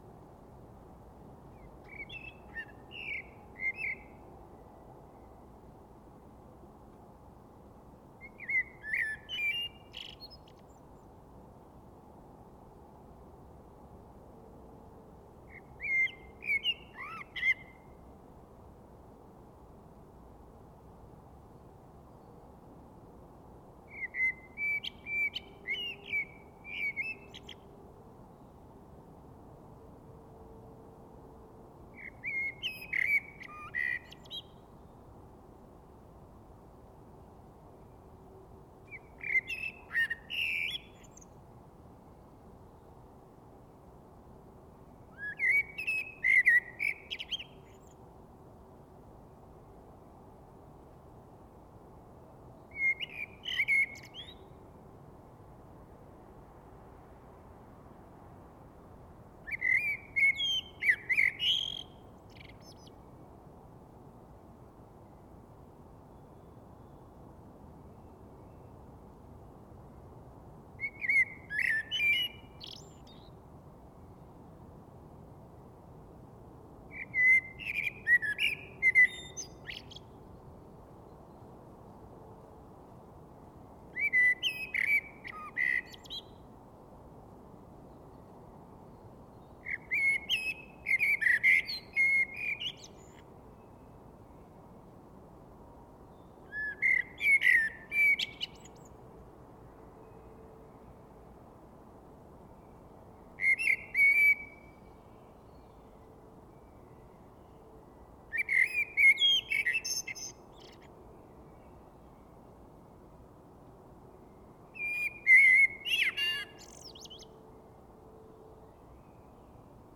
Fast zögerlich fängt eine Amsel an zu singen. Um sie herum ist es fast komplett still. Nach und nach gesellen sich andere Amseln mit ihrem Gesang zu ihr, weit in der Ferne.
Almost hesitantly, a blackbird begins to sing. It's almost completely silent around the singer. One after the other, more blackbirds join it with their singing, far in the distance.